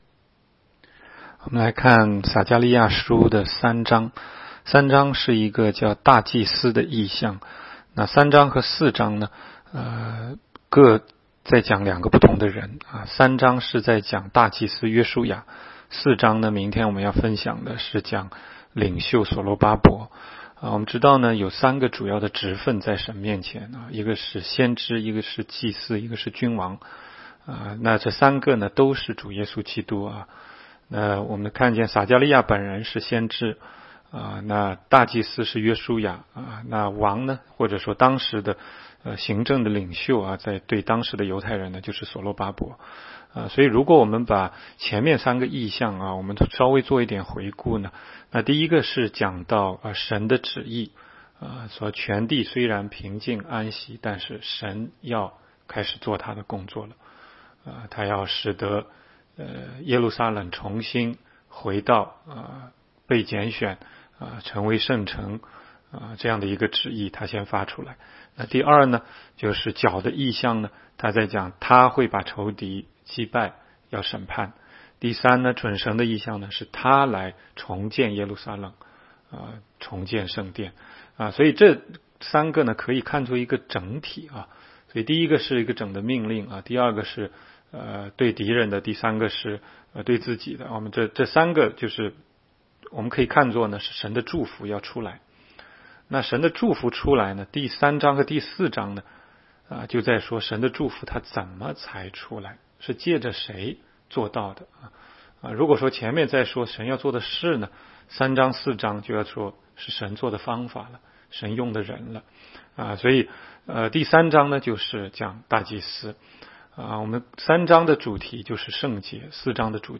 每日读经